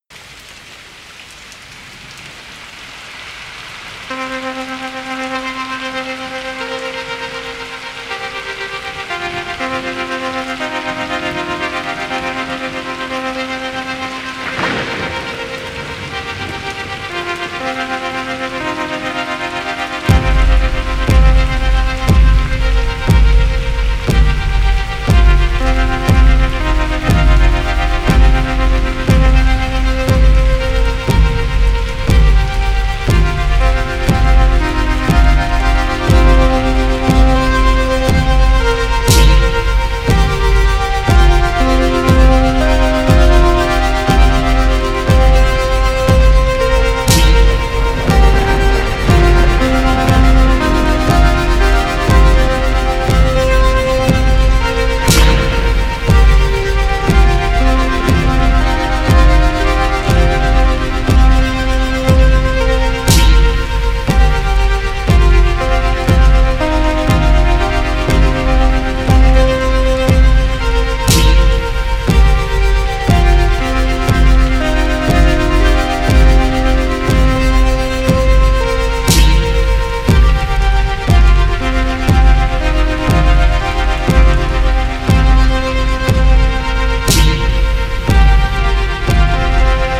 acid, techno, noise
creating an exciting soundtrack to a contorted world
Wave EBM